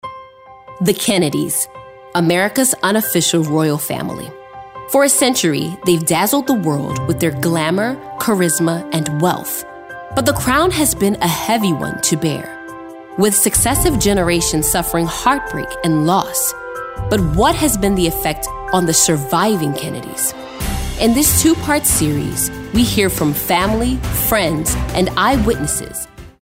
announcer, authoritative, compelling, promo, serious, storyteller, young adult